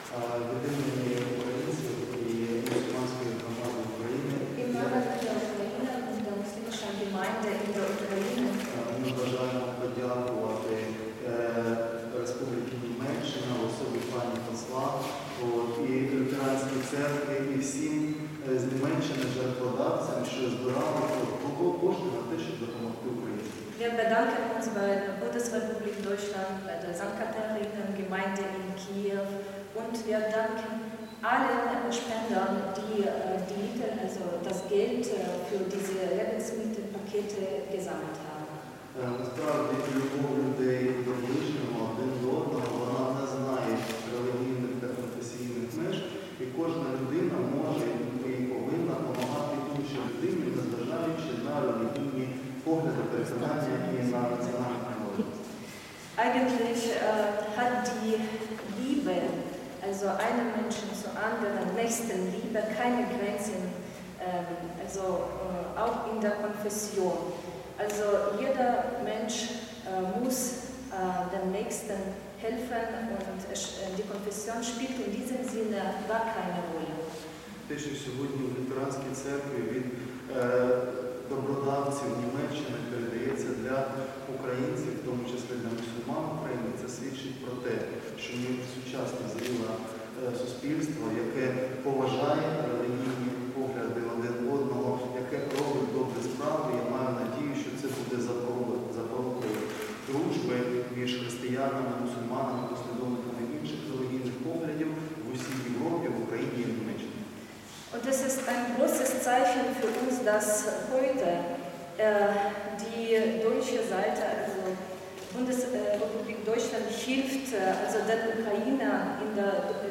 Rede zum Nachhören – mit deutscher Übersetzung)